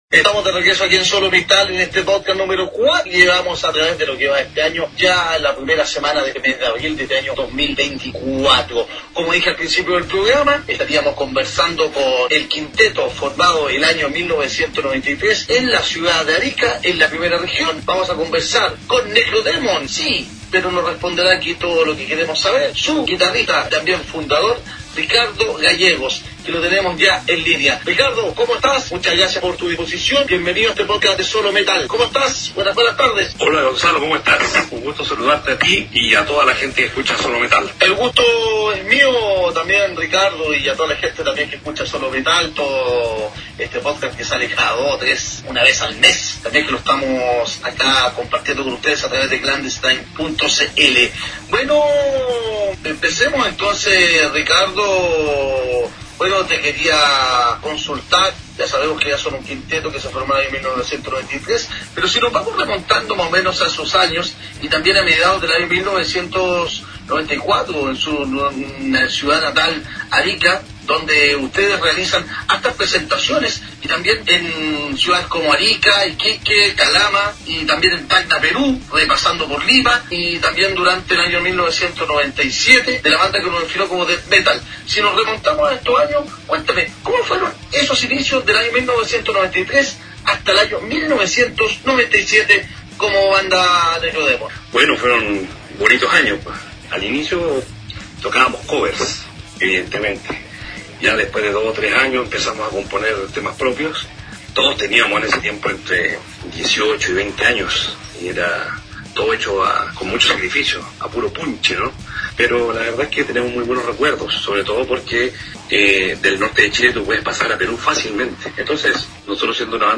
Entrevista
necrodemon-entrevista-.mp3